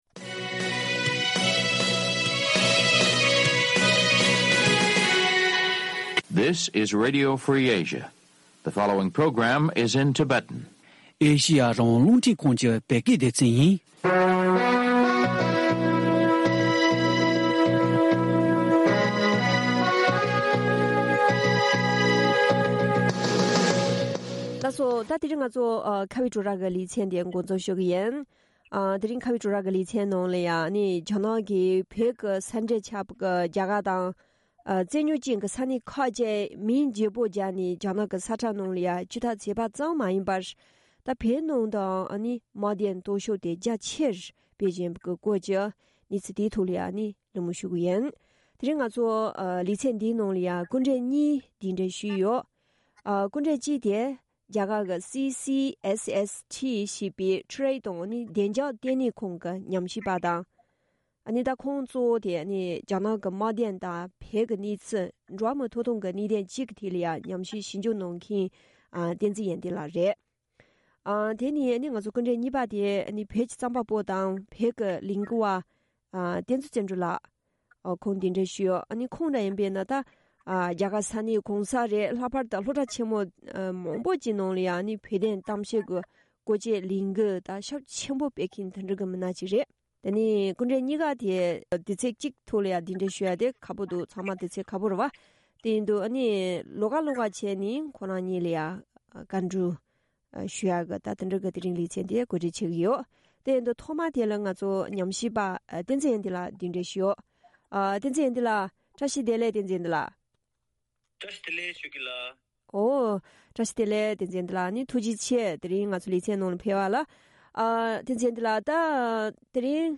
ཁ་བའི་གྲོས་རྭའི་ལེ་ཚན་ནང་།